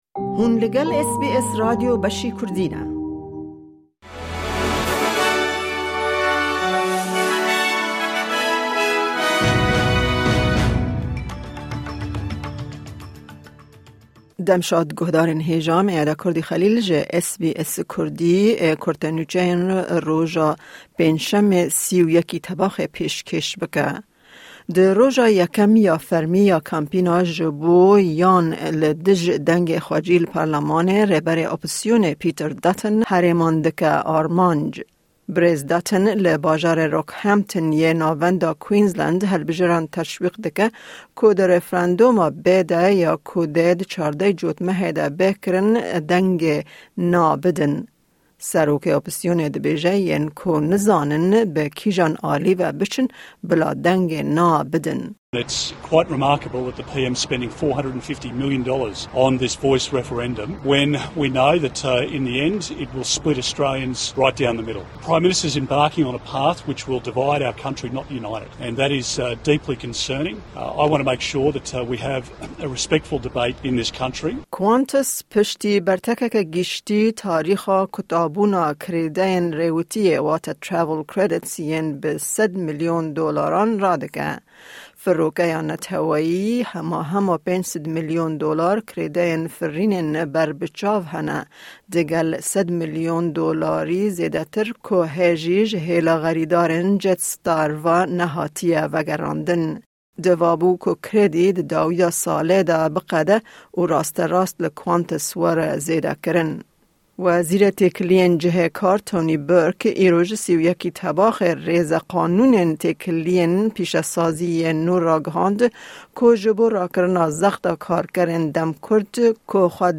Kurte Nûçeyên roja Pêncşemê 31î Tebaxê